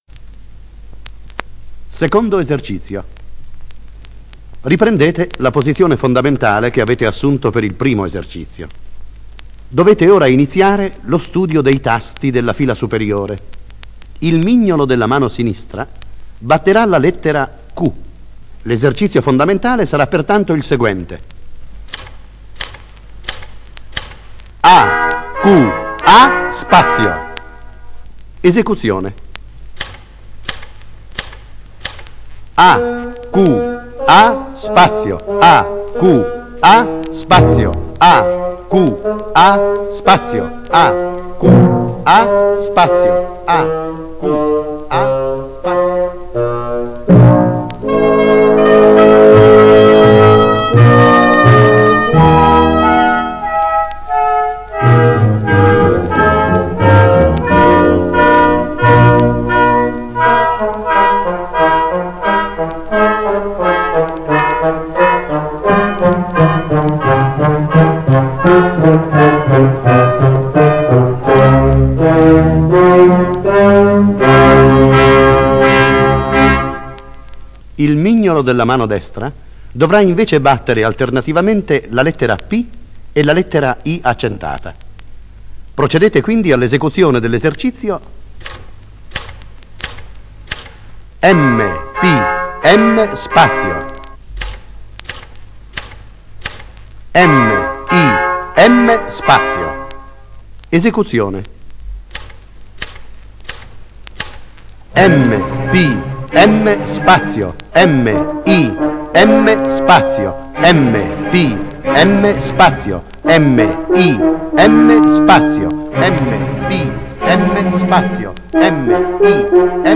Olivetti, Instructions for the use of the Olivetti Lettera 22 Typewriter Musica per parole, Metodo Olivetti per Lettera 22, Olivetti S.p.A., Ivrea, 1959, Lp 33 giri. Esercizi di dettatura letti da Mario Soldati.